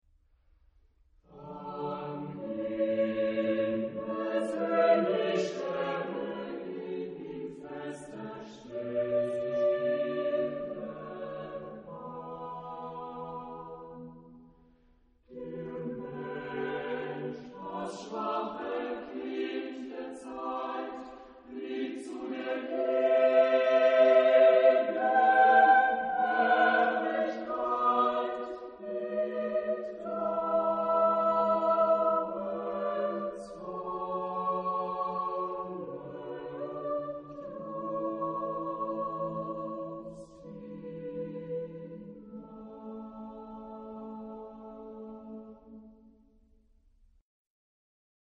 Charakter des Stückes: mäßig
Chorgattung: SATB  (4 gemischter Chor Stimmen )
Tonart(en): A-Dur